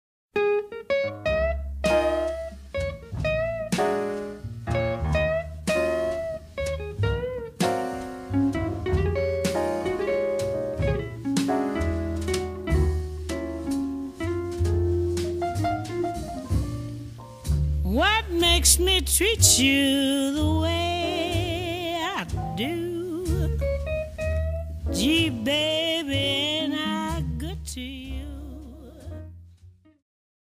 CD2の(12)-(20)はボーナス･トラックで、1957年7月、ニューポート･ジャズ祭でのステージの模様を収録。